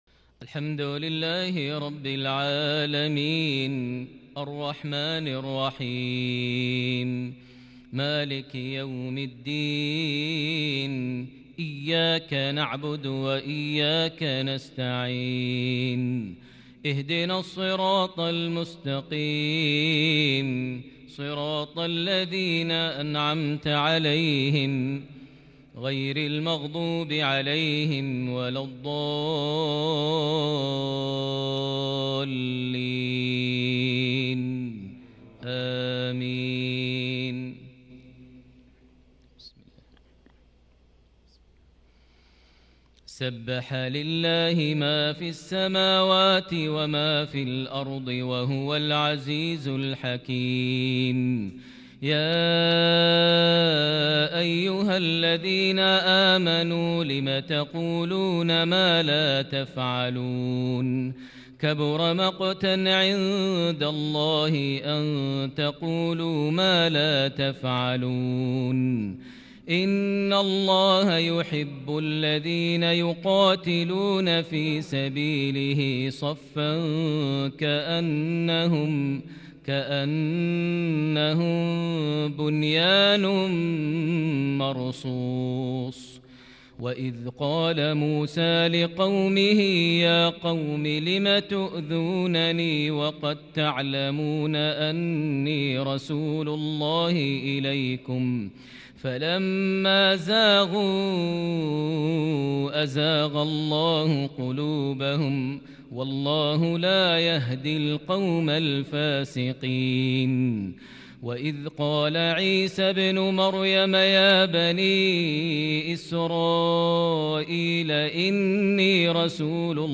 صلاة العشاء ١٧ شعبان ١٤٤١هـ سورة الصف > 1441 هـ > الفروض - تلاوات ماهر المعيقلي